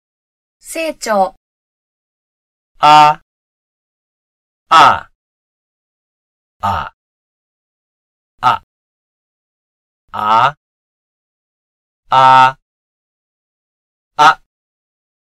台湾語の声調は7つです。
seichou_tones.mp3